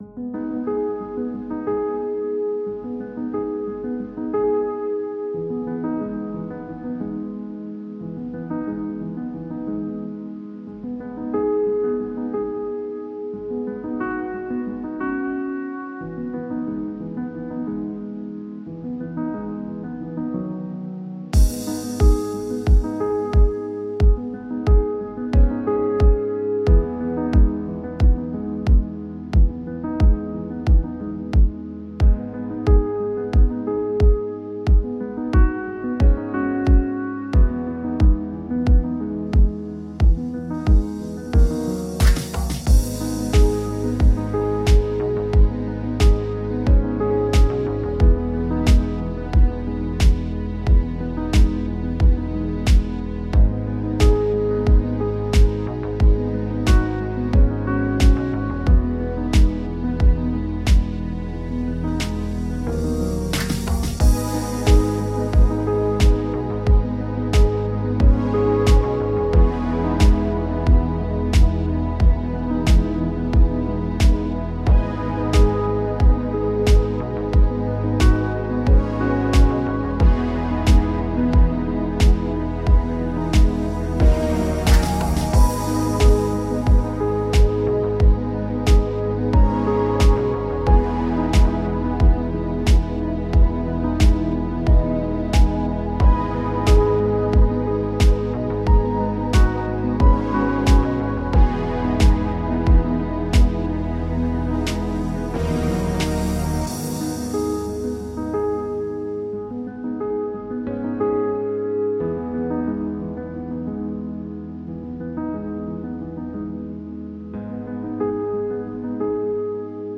Позитивный музыкальный фон для селфи-интервью и влогов